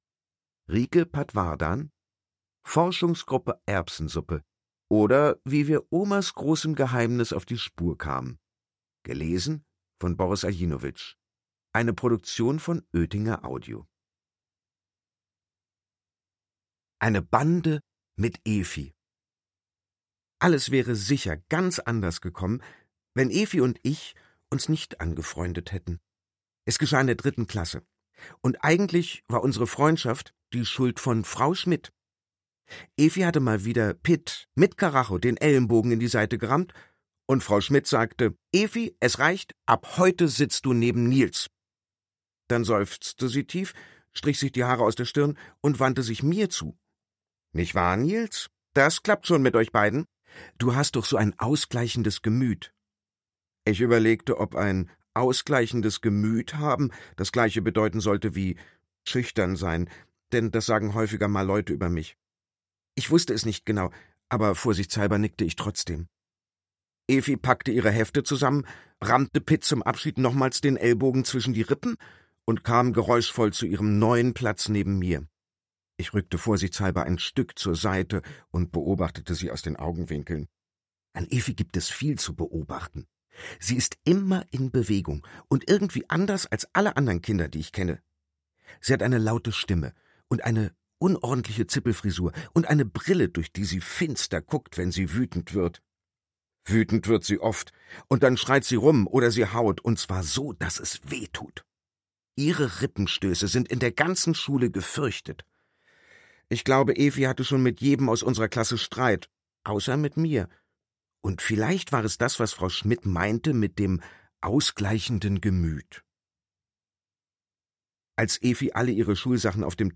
Boris Aljinovic (Sprecher)